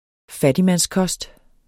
Udtale [ -ˌkʌsd ]